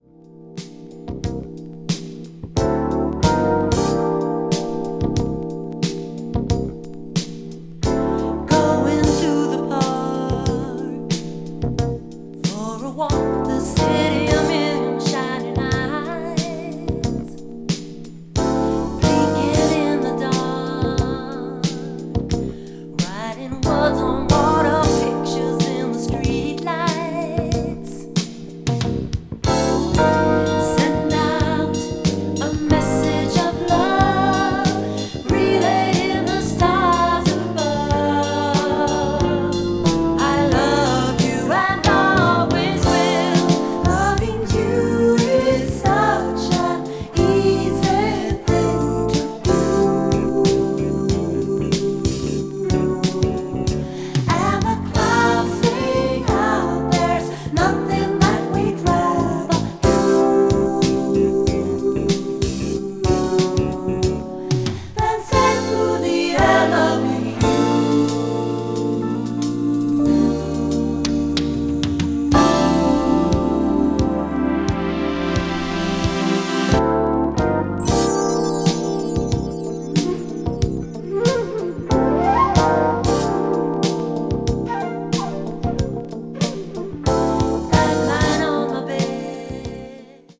crossover   fusion   jazz groove   mellow groove   pop   r&b